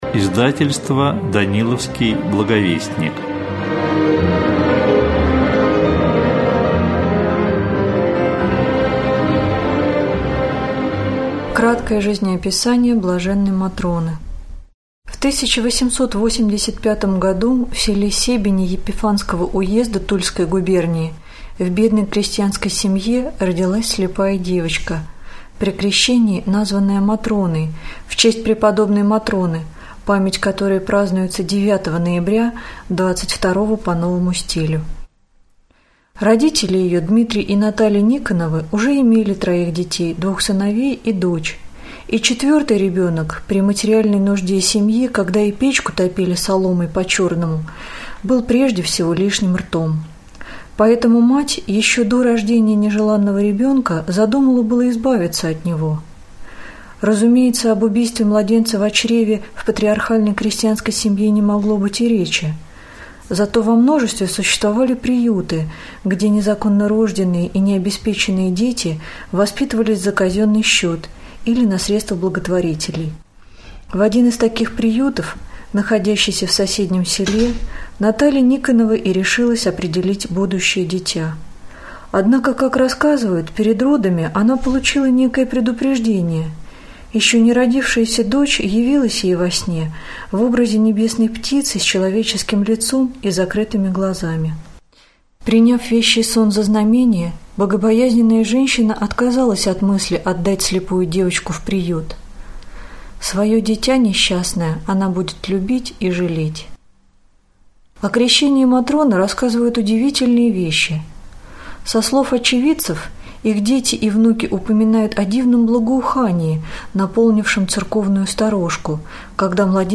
Аудиокнига О жизни и чудесах блаженной Матроны Московской | Библиотека аудиокниг